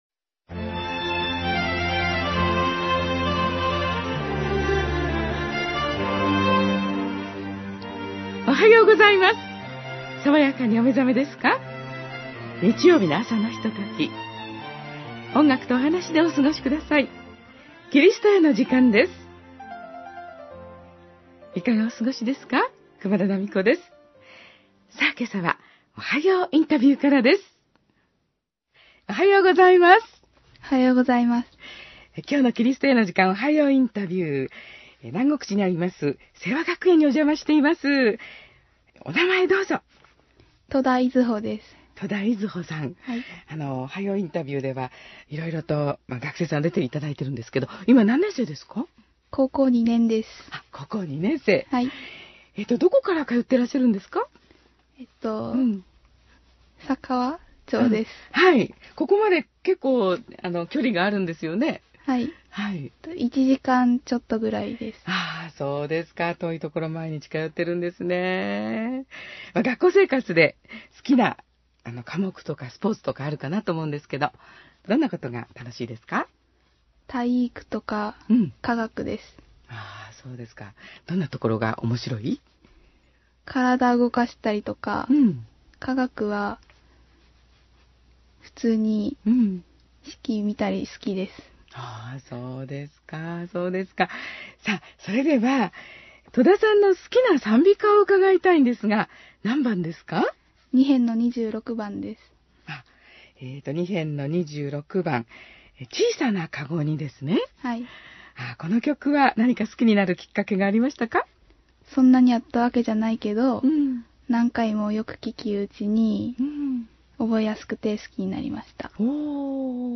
キリストへの時間 2014年8月17日放送